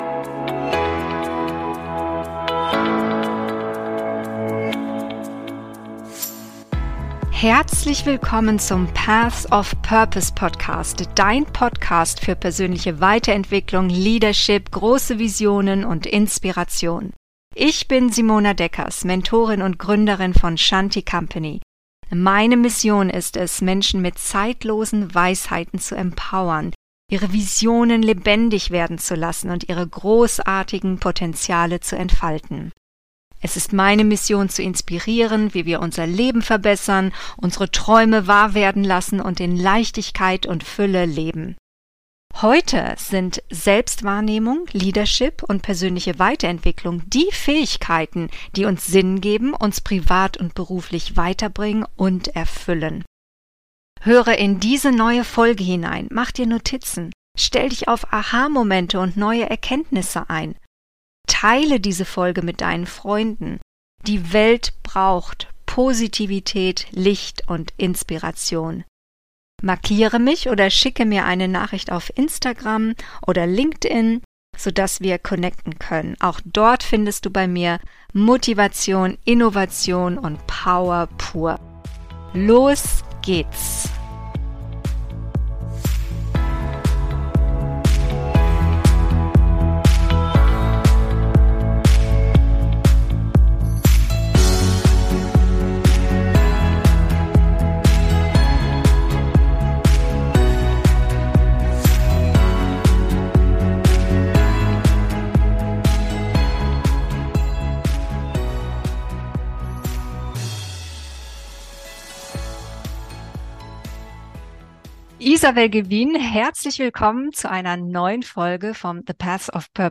Mission For Justice: Soziale Gerechtigkeit und Chancengleichheit - Interview